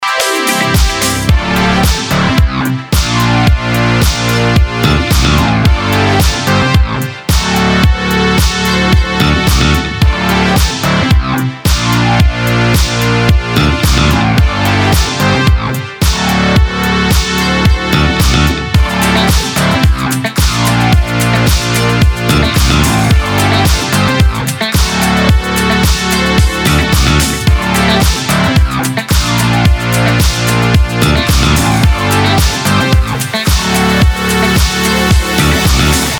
• Качество: 320, Stereo
ритмичные
громкие
Electronic
Стиль: synthwave, retrowave